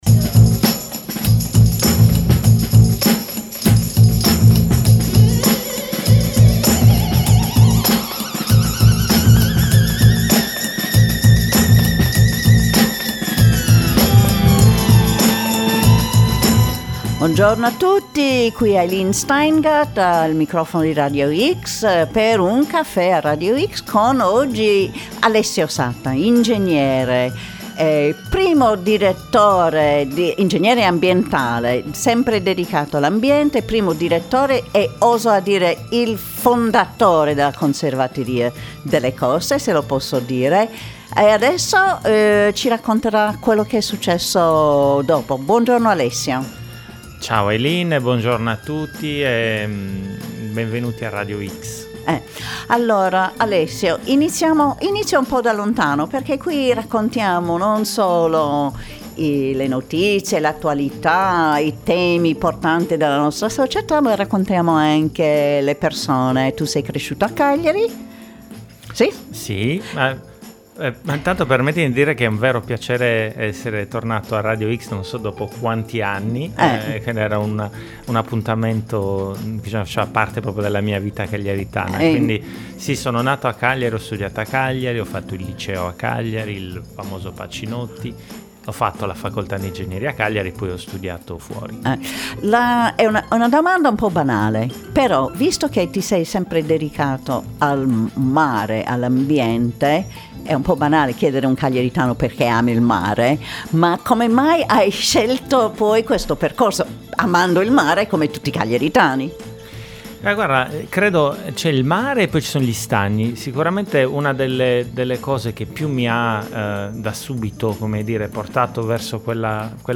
Identità e innovazione, la sfida della tutela costiera :: Un caffè a Radio X